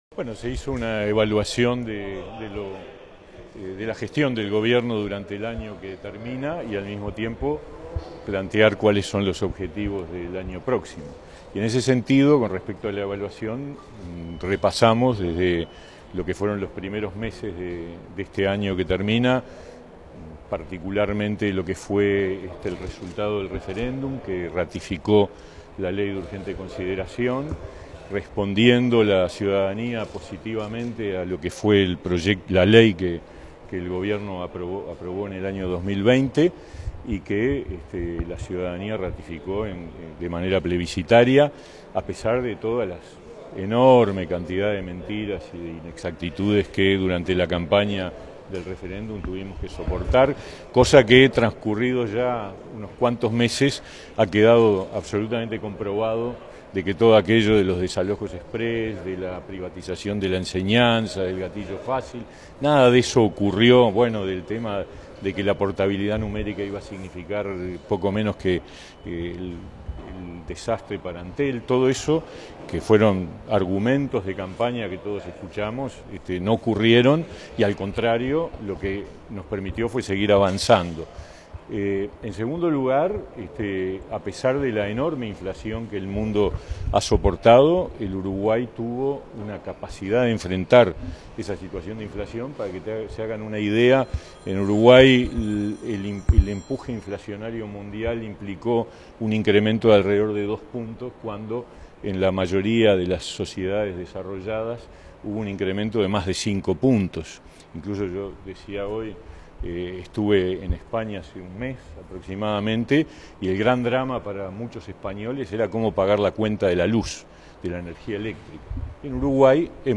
Declaraciones a la prensa del ministro de Trabajo y Seguridad Social, Pablo Mieres
Tras participar en el Consejo de Ministros, este 19 de diciembre, el ministro de Trabajo y Seguridad Social, Pablo Mieres, dialogó con la prensa.